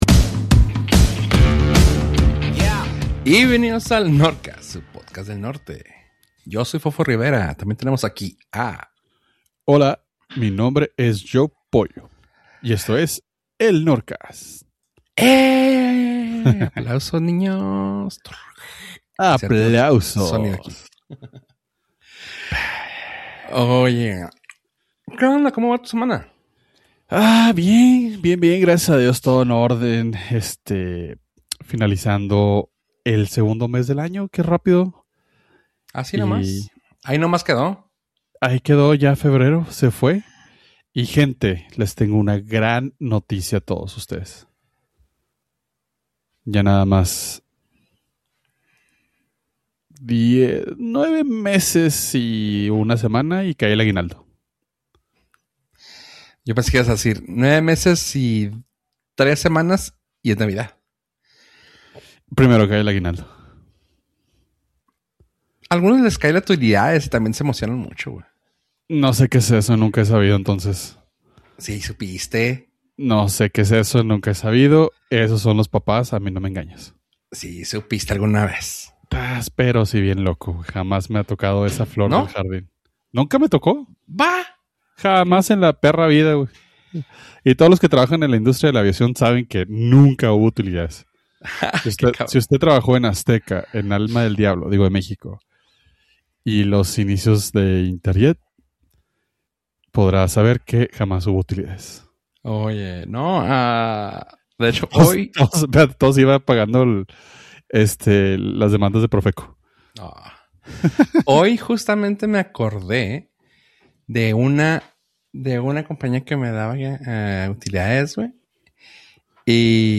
Nortcast Nortcast Un podcast de entretenimiento, tecnología y cultura pop. Presentado desde el Norte (Ciudad Juárez, Chihuahua).